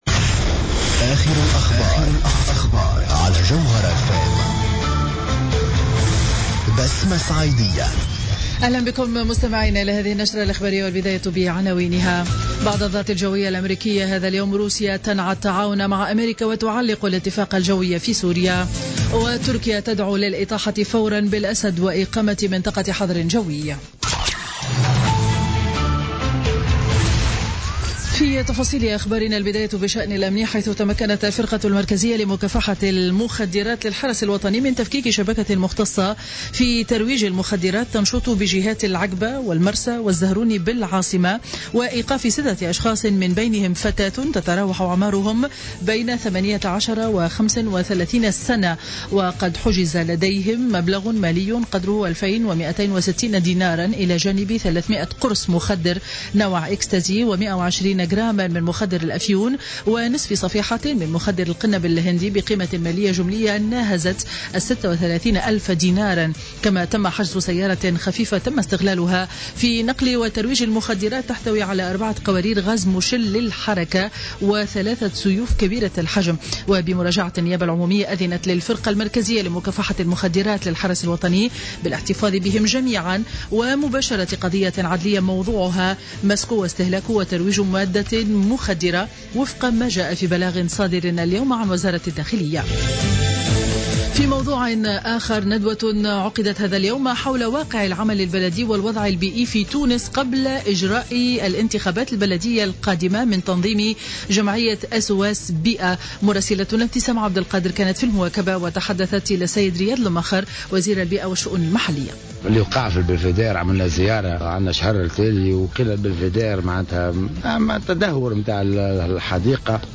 نشرة أخبار منتصف النهار ليوم الجمعة 7 أفريل 2017